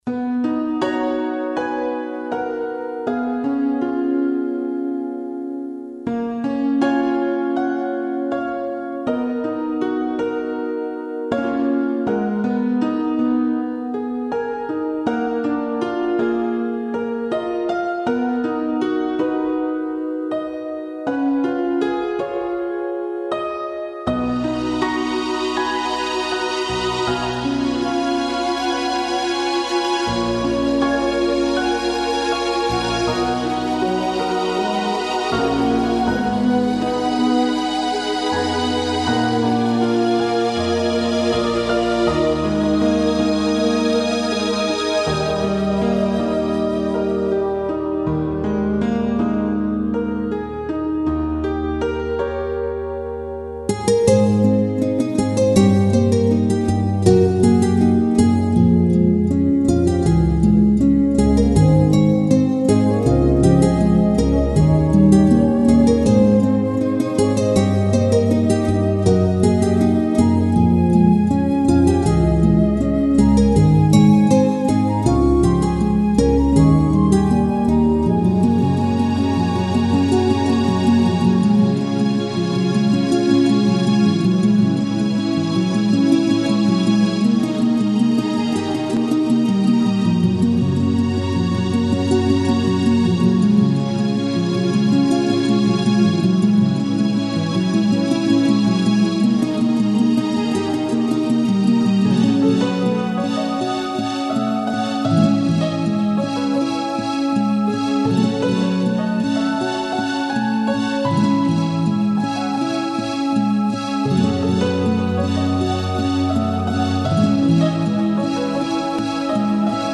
こちらでは、ゲーム内で使われているＢＧＭを何曲か、お聴かせしちゃいます。
そんな切なさに、この曲が添えられます。